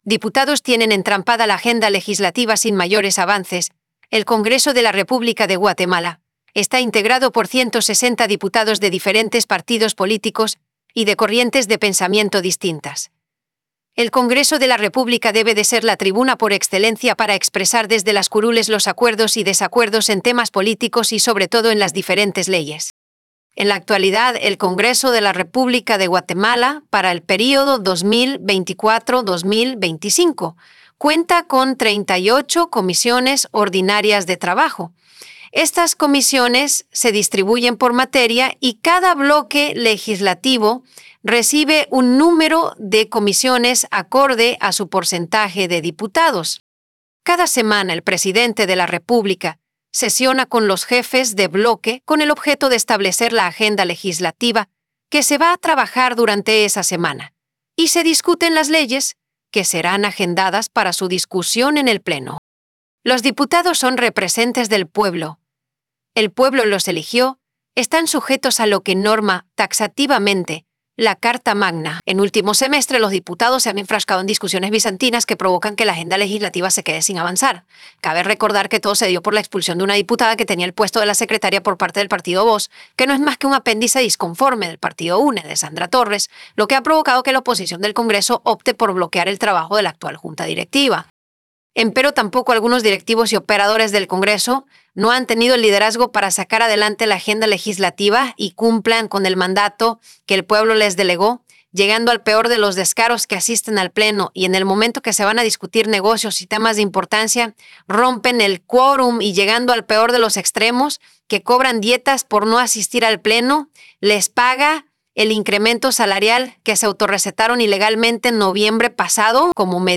PlayAI_Diputados_tienen_entrampada_la_agenda.wav